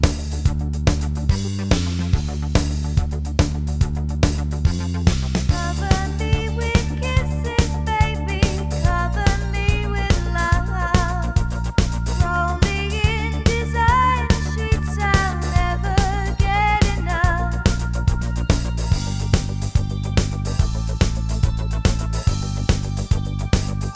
Short Version Pop (1980s) 4:23 Buy £1.50